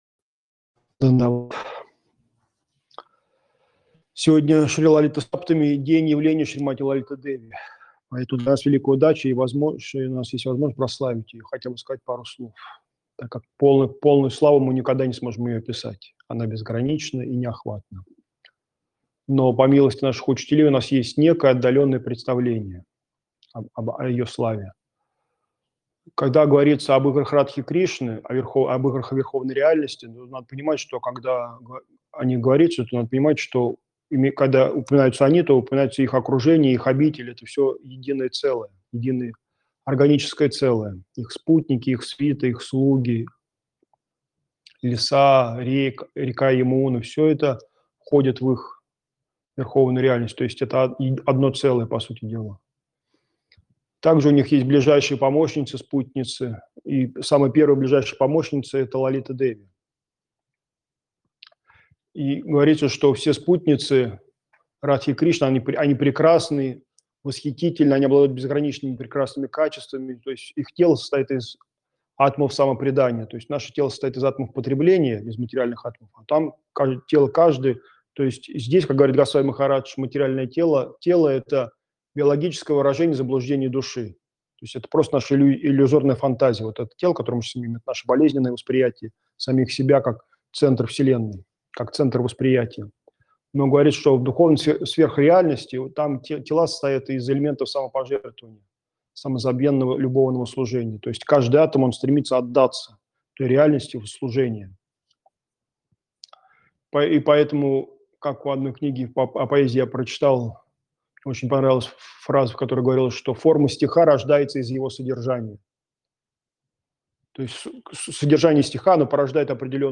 Место: Кисельный (Москва)
Лекции полностью